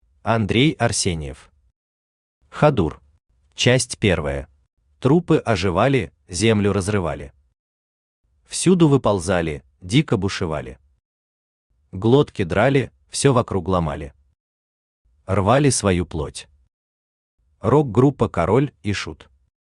Аудиокнига Хадур | Библиотека аудиокниг
Aудиокнига Хадур Автор Андрей Александрович Арсеньев Читает аудиокнигу Авточтец ЛитРес.